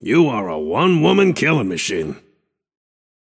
Shopkeeper voice line - You are a one woman killin‘ machine
Shopkeeper_hotdog_t4_mcginnis_02.mp3